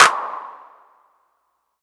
• Hand Clap Sound D# Key 20.wav
Royality free clap sound clip - kick tuned to the D# note. Loudest frequency: 2617Hz
hand-clap-sound-d-sharp-key-20-bKk.wav